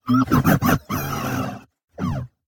惊讶且尴尬的哔哔声